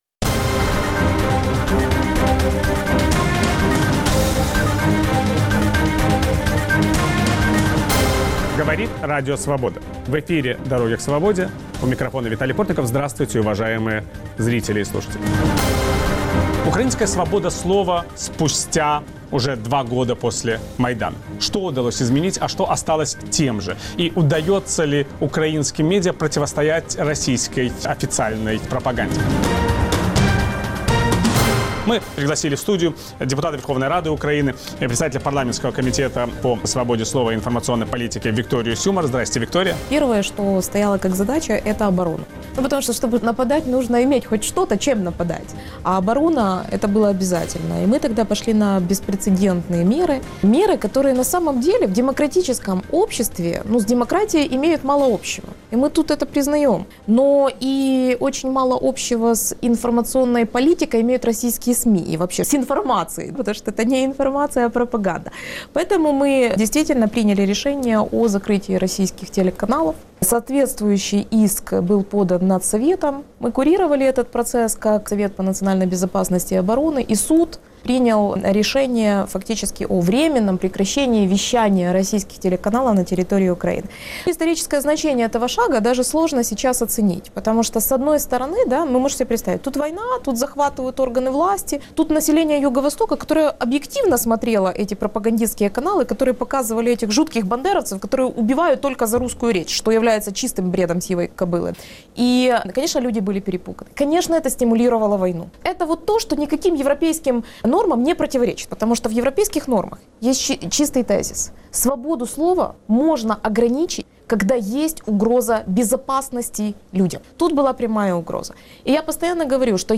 Гость программы "Дороги к свободе" - председатель парламентского комитета по свободе слова и информационной политике, депутат Верховной Рады Украины Виктория Сюмар.